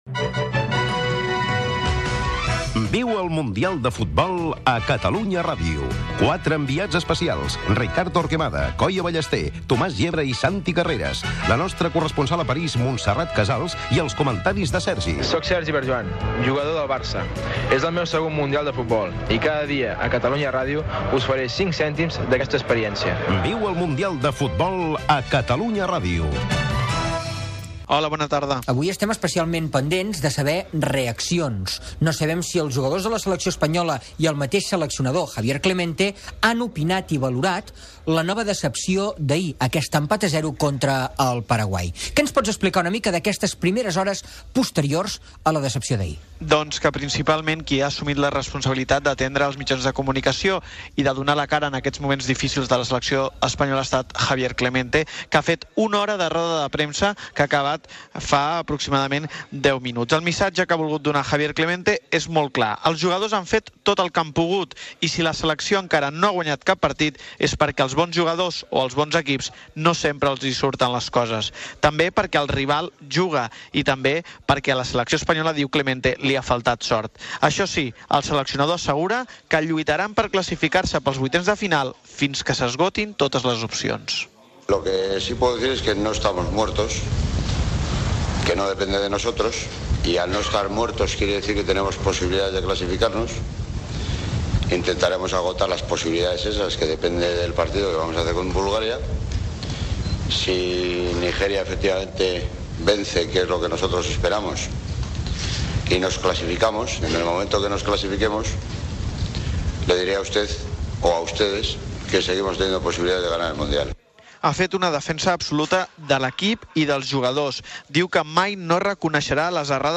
Esportiu
FM